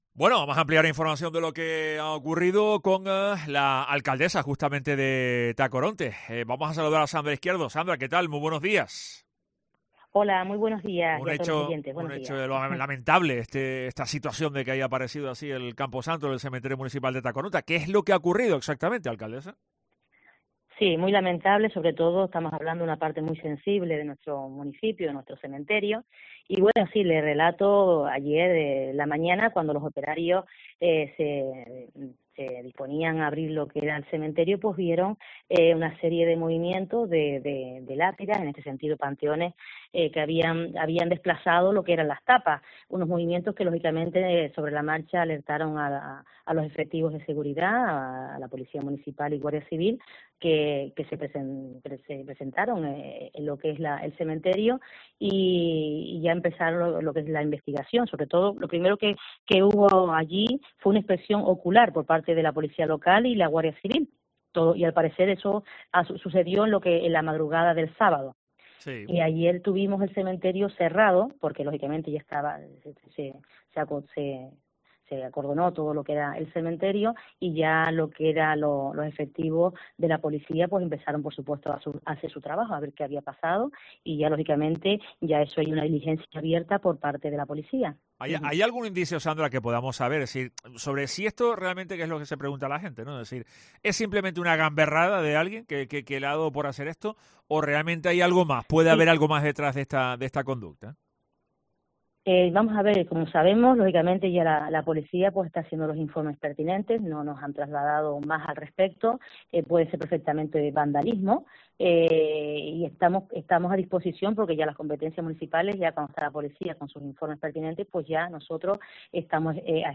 Según ha confirmado la alcaldesa Sandra Izquierdo hoy en Herrera en COPE Tenerife, hasta 4 tapas de algunas lápidas en panteones familiares aparecían desplazadas de su lugar junto con algunos corrimientos de tierra, cuando ayer domingo se procedió a la apertura del camposanto.